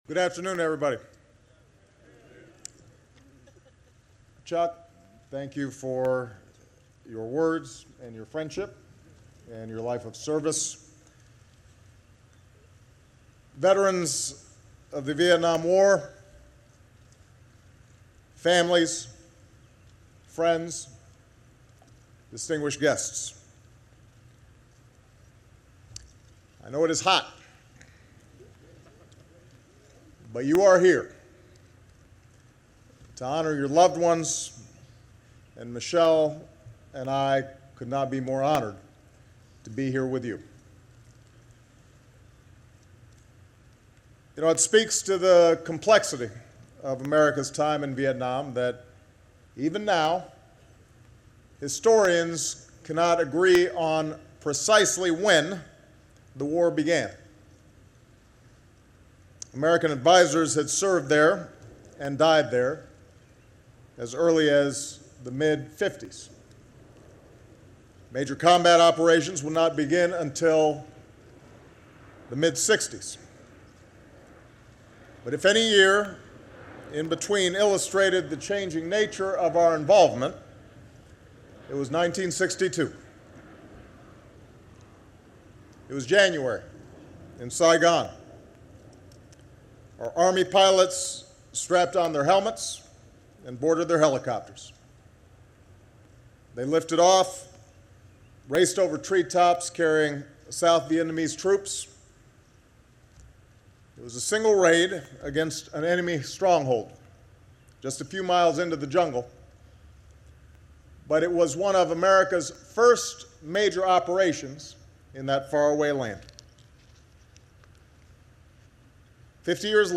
U.S. President Barack Obama speaks at a ceremony commemorating the 50th anniversary of the Vietnam War